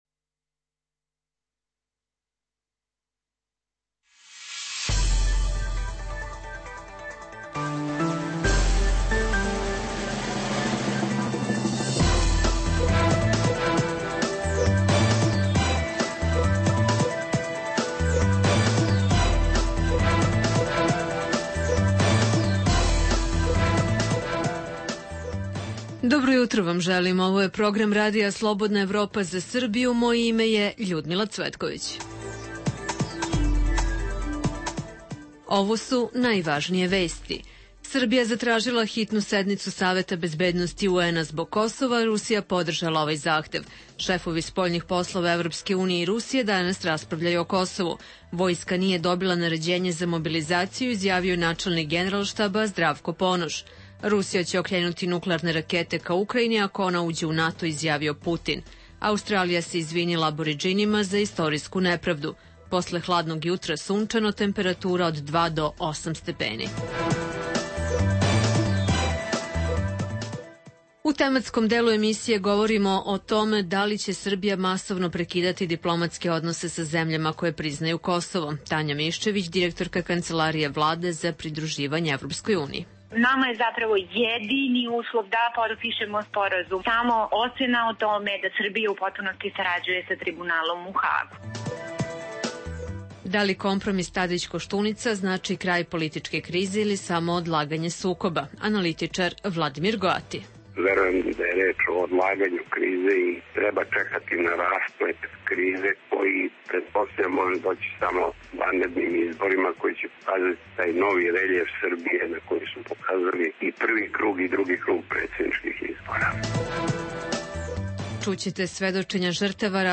Da li je nakon dogovora državnog vrha oko Kosova otklonjena kriza vladajuće koalcije, nastala oko potpisivanja prelaznog sporazuma sa EU ili je samo odložena. Čućete i svedočenja žrtava ratnih zločina iz BiH, Hrvatske i sa Kosova.